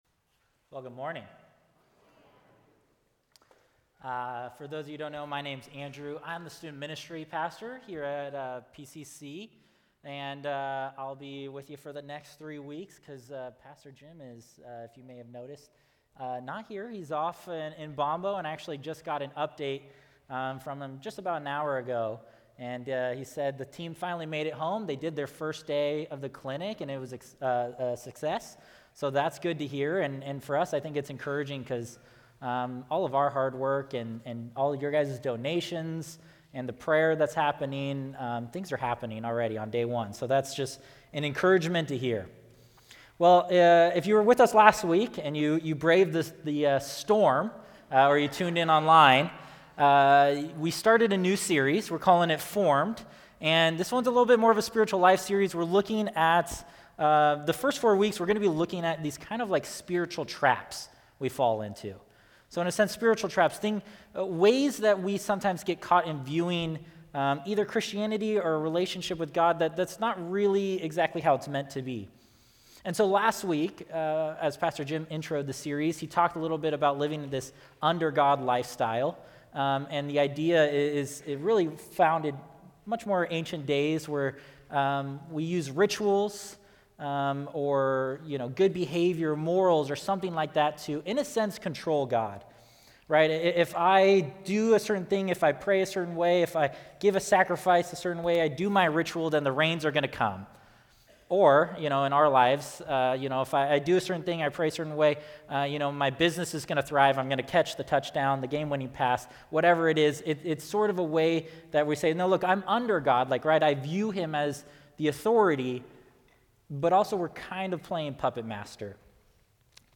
A message from the series "Formed."